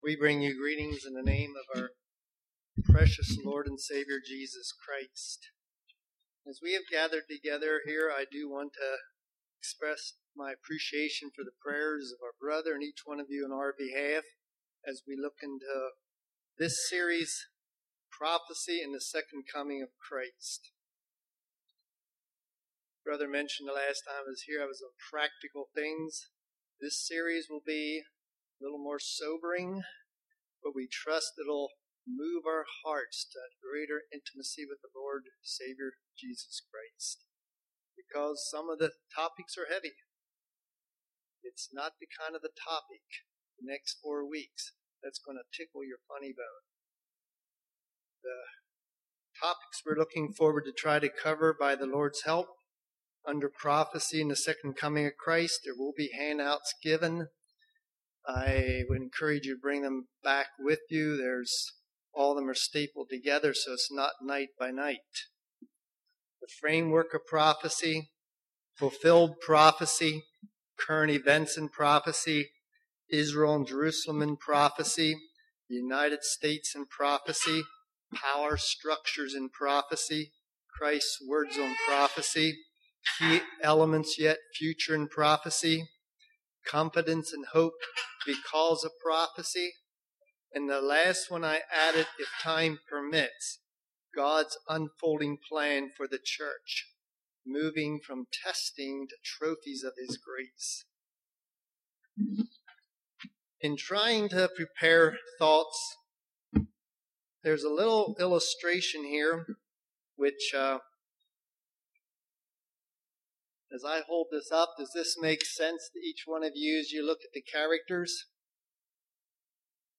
Winter Bible Study 2010 Service Type: Winter Bible Study « Prophecy and the Second Coming of Christ